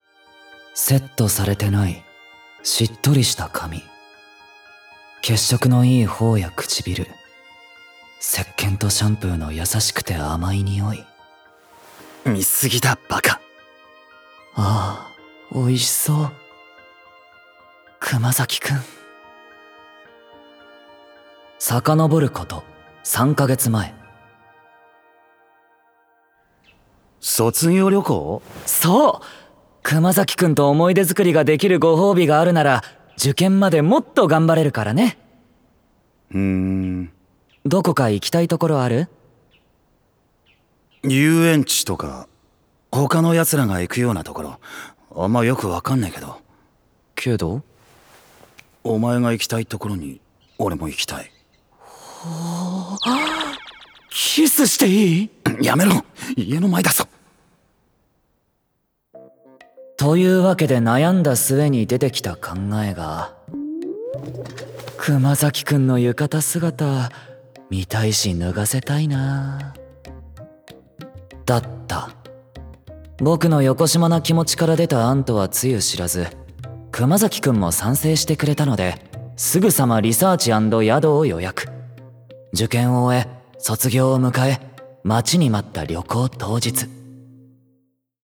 ドラマCD「寺野くんと熊崎くん2」